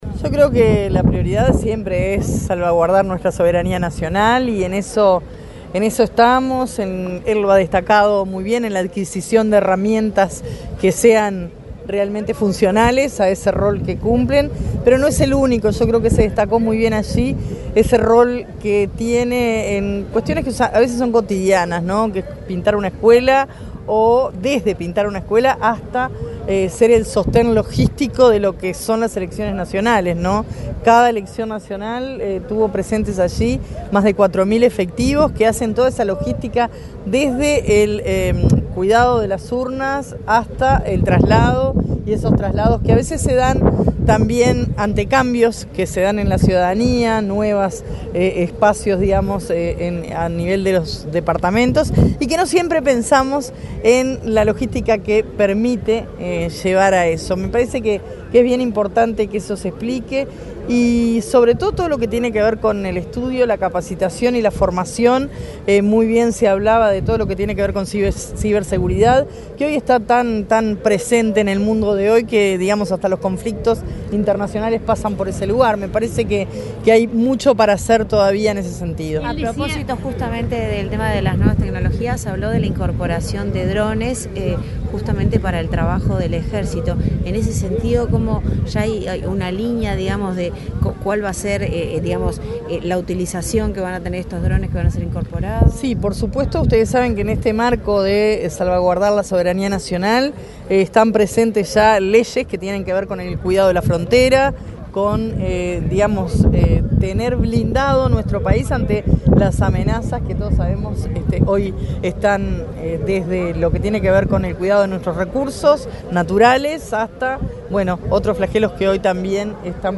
Declaraciones de la ministra de Defensa Nacional, Sandra Lazo
La ministra de Defensa Nacional, Sandra Lazo, dialogó con la prensa, luego de asistir a la conmemoración del Día del Ejército Nacional.